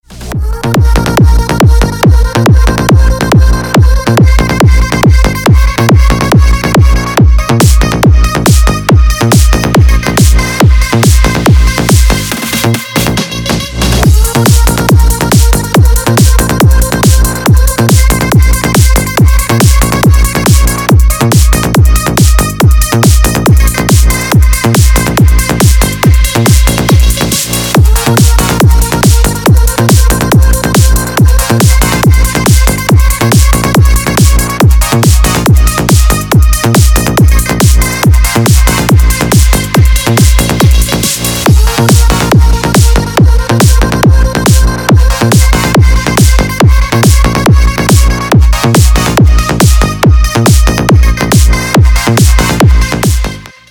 Громкий быстрый рингтон